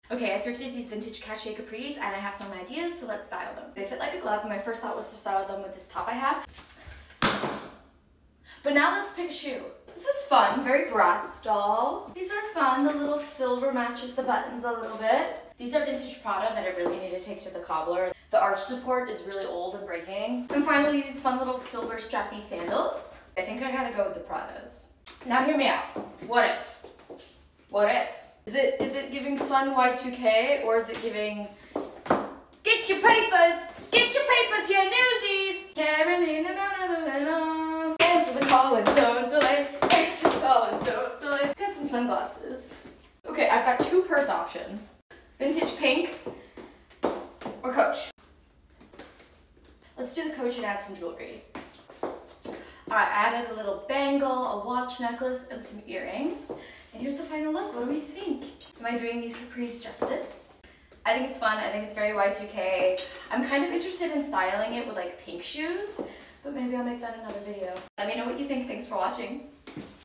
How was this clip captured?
Not sure I love the audio quality when I talk in the hallway!